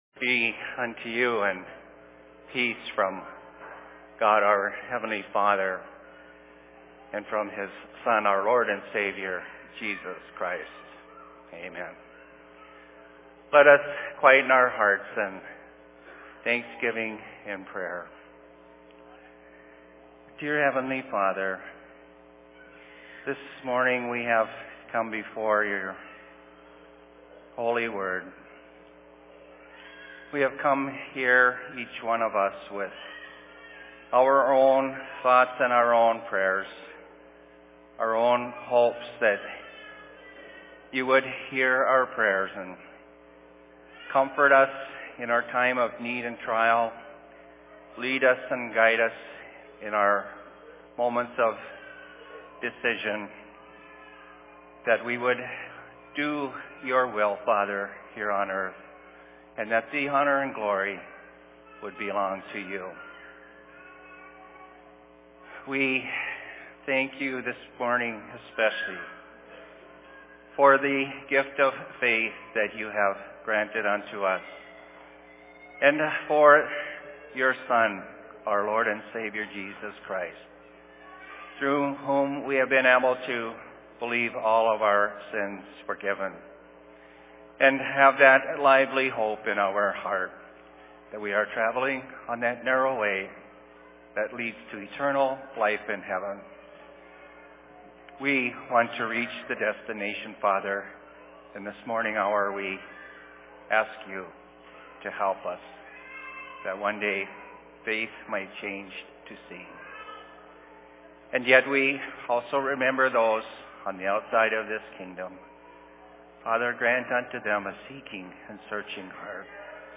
Sermon in Rockford 11.08.2013
Location: LLC Rockford